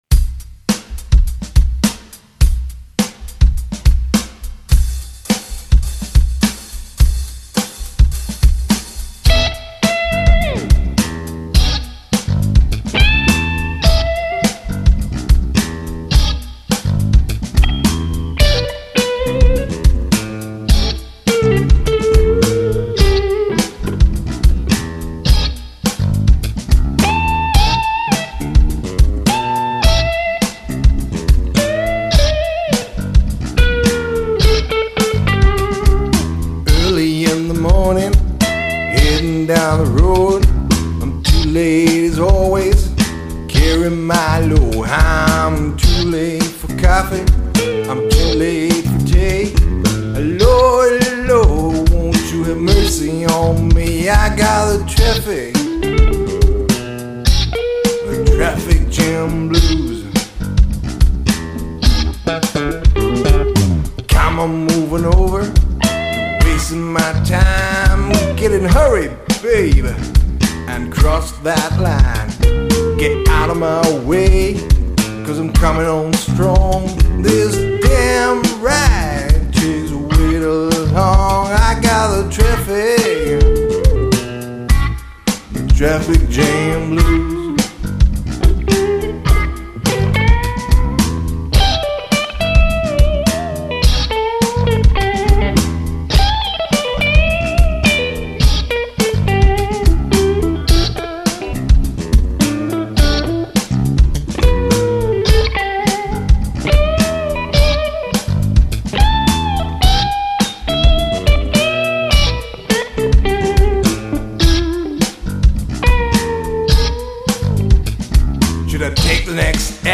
Hier habe ich meine alte Ibanez Semi durch den Womanizer geschickt.